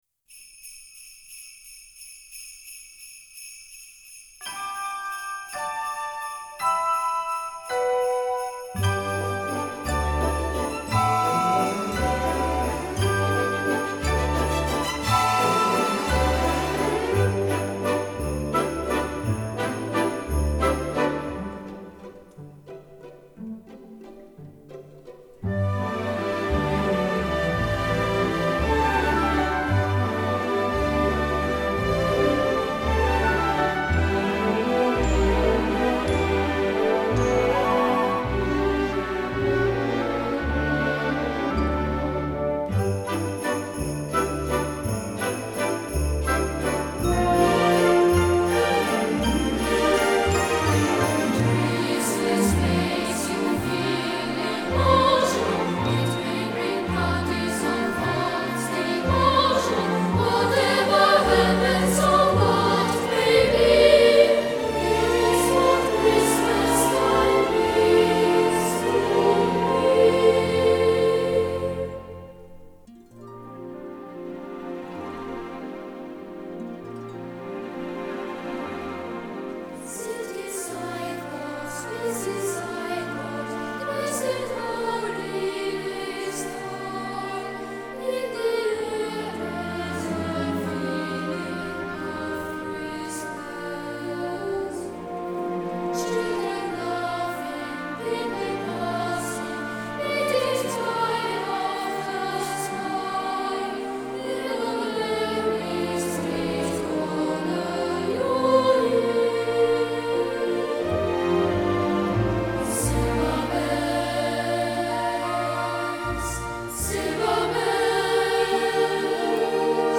Genre:Easy Listening
Sub-Genre:Classical Pop
Type:Christmas